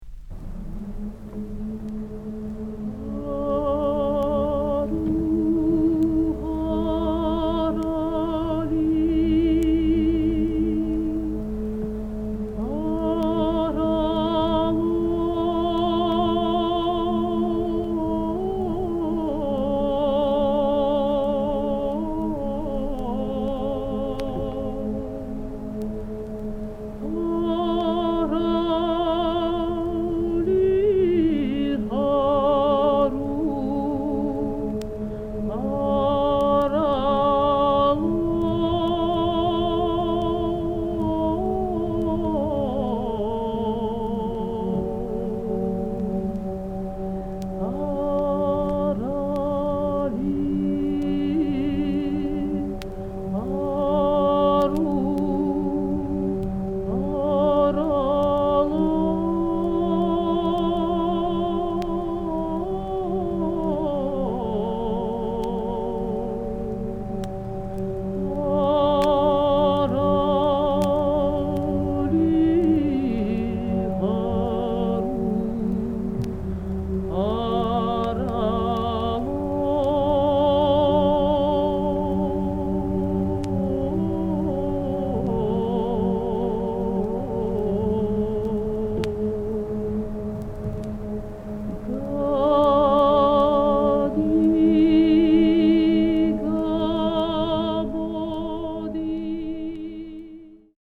多声合唱の最も古い形のひとつと云われるジョージアの伝承歌。
現地録り